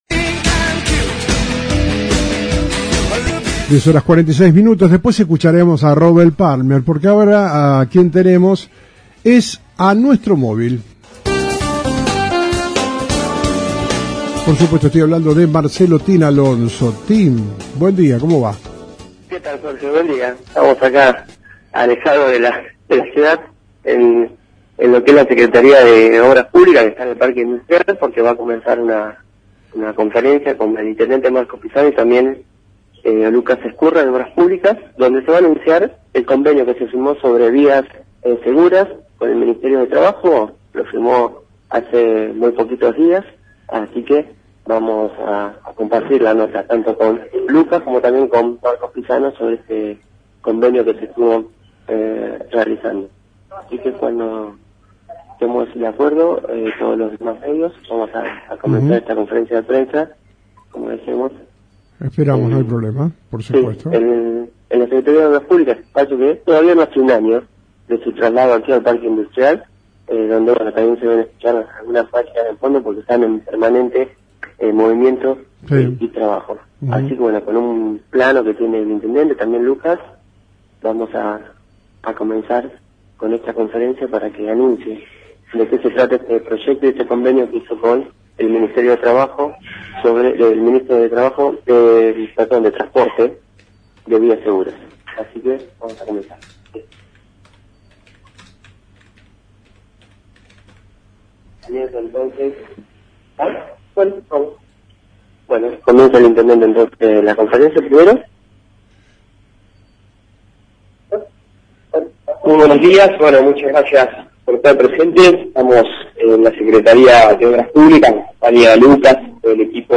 Conferencia de Prensa, Intendente Marcos Pisano y Sec. de Obras Públicas Lucas Ezcurra